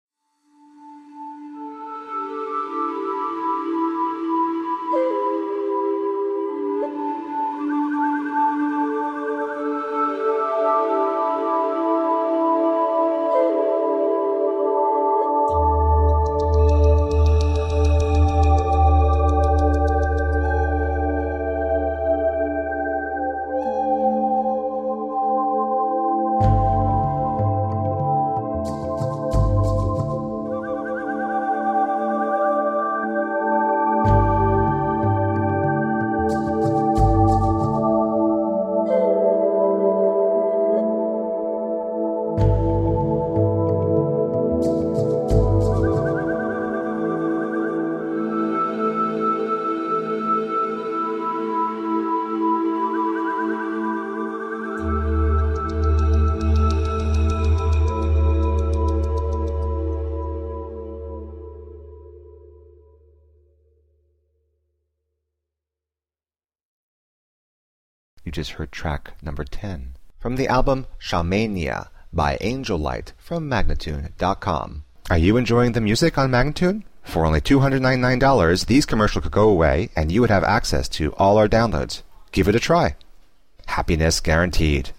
New age/world music for mankind from russia.
Tagged as: New Age, Inspirational, Chillout, Massage